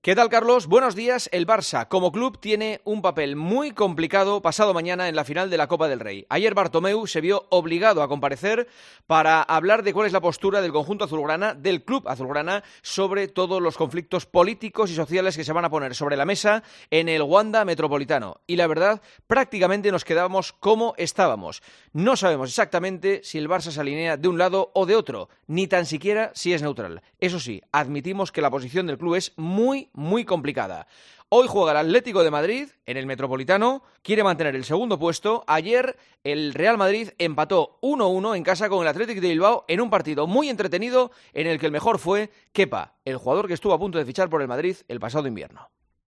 El comentario de Juanma Castaño
Escucha el comentario sobre la actualidad deportiva del director de 'El Partidazo' de COPE